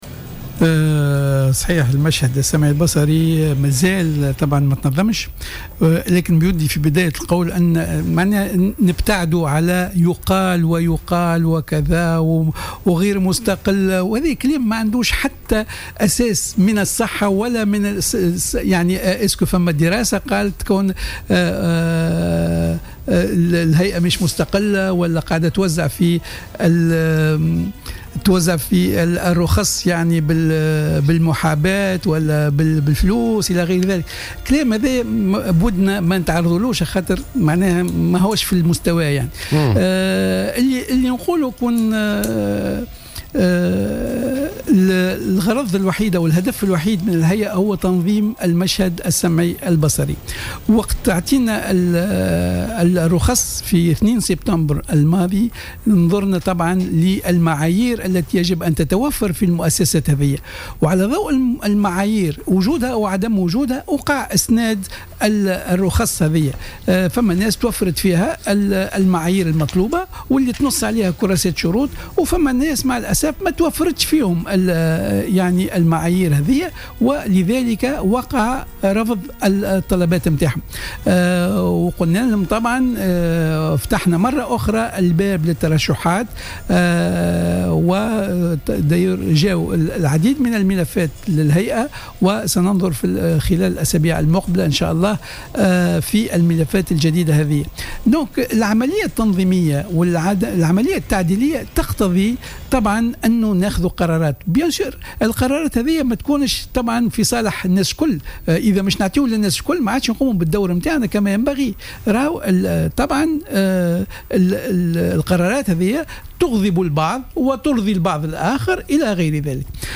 أكد رئيس الهيئة العليا المستقلة للاتصال السمعي البصري،النوري اللجمي،في مداخلة له اليوم في برنامج "بوليتيكا" أن الهيئة ستنظر خلال الأسابيع المقبلة في ملفات إسناد رخص جديدة لبعض المؤسسات الإعلامية.